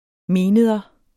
Udtale [ ˈmeːˌneːðʌ ]